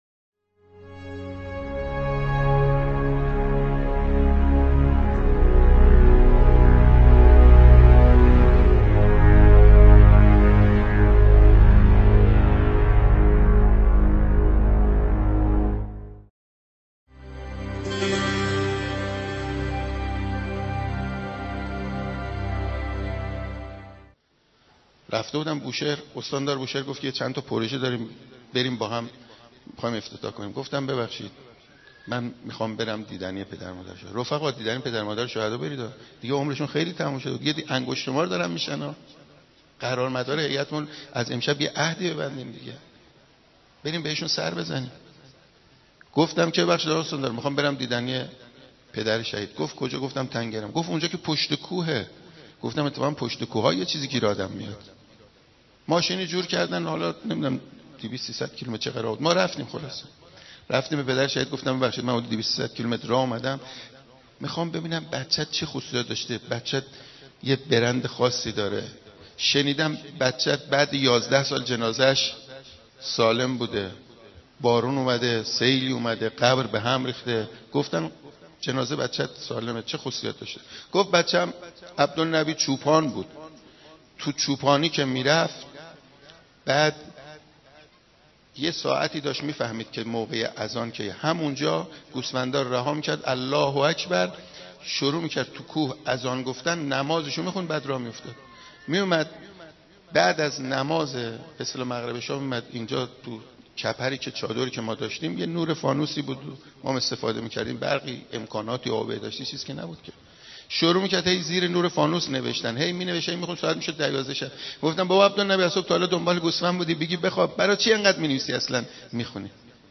روایتگری